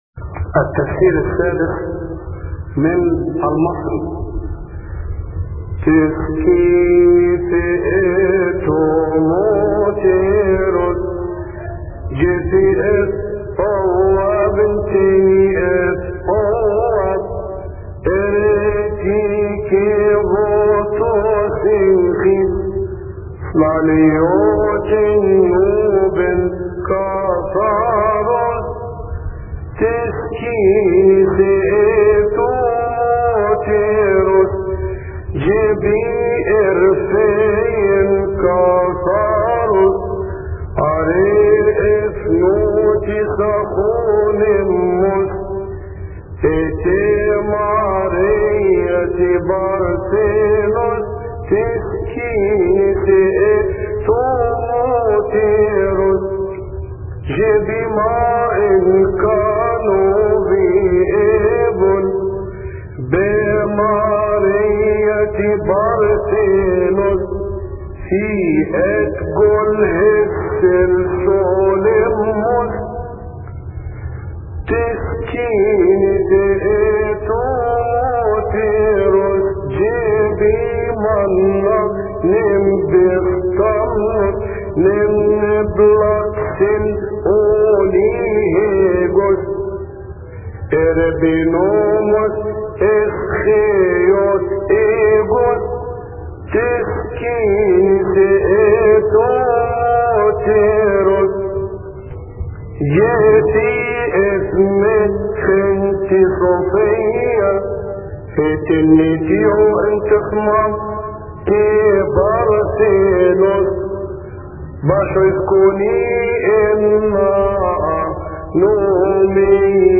المرتل
التفسير السادس من المصري لثيؤطوكية السبت يصلي في تسبحة عشية أحاد شهر كيهك